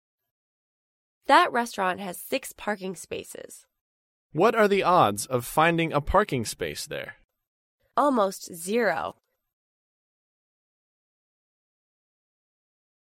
在线英语听力室高频英语口语对话 第501期:寻找停车场(2)的听力文件下载,《高频英语口语对话》栏目包含了日常生活中经常使用的英语情景对话，是学习英语口语，能够帮助英语爱好者在听英语对话的过程中，积累英语口语习语知识，提高英语听说水平，并通过栏目中的中英文字幕和音频MP3文件，提高英语语感。